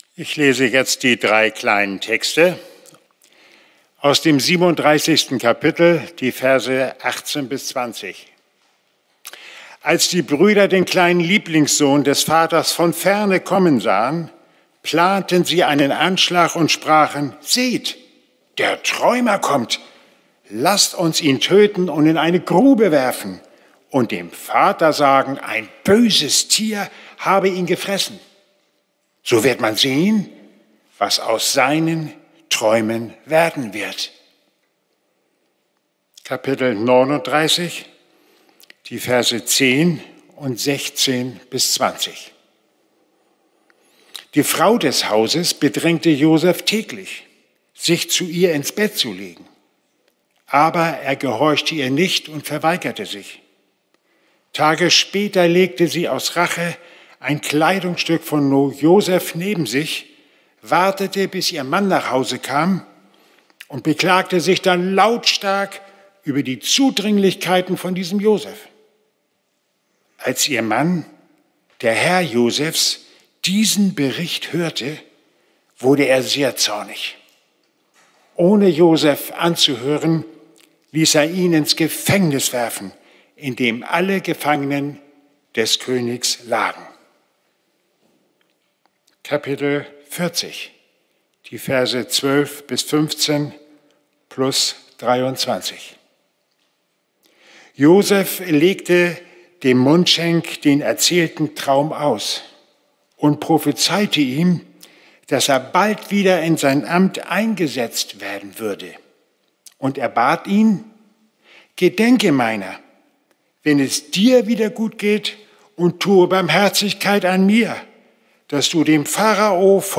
04-Predigt-4.mp3